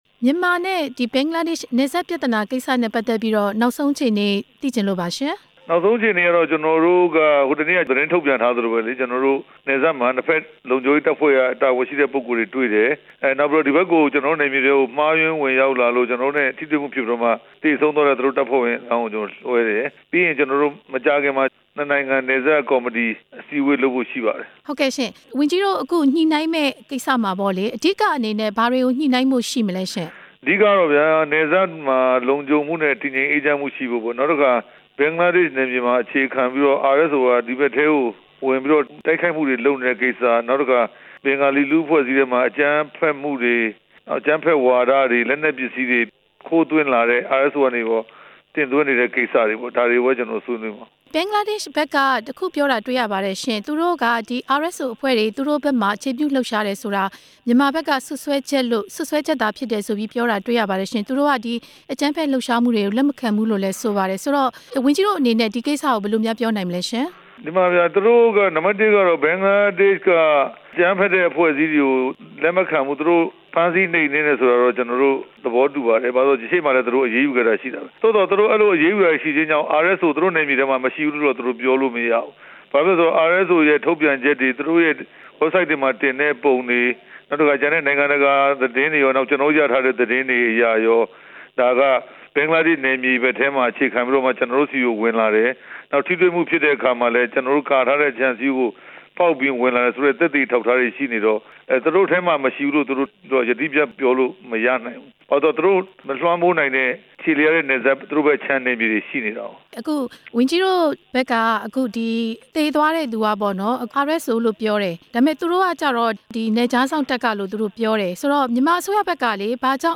ပြန်ကြားရေး ဒုဝန်ကြီး ဦးရဲထွဋ်နဲ့ မေးမြန်းချက်